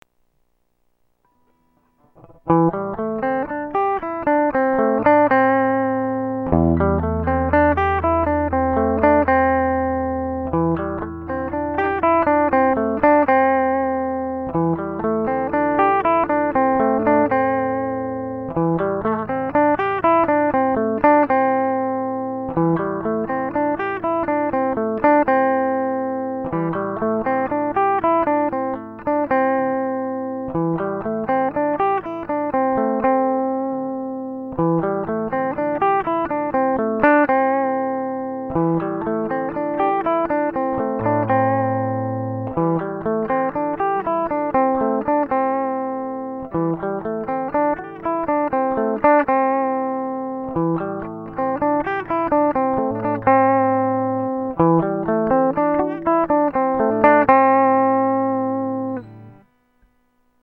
ちなみにこれはテンポ100で録音いたしました☆
U_practice_01.mp3